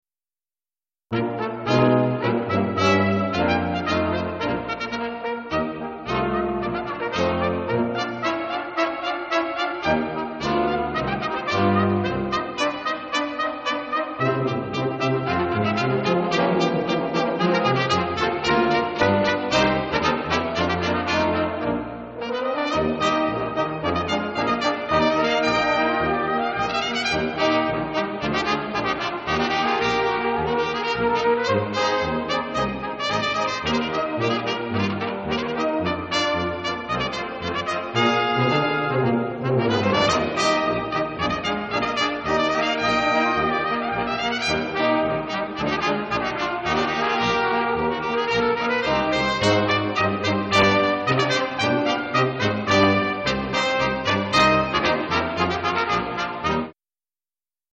St. Rose Concert Series 2006
Polished Brass
St. Rose Catholic Church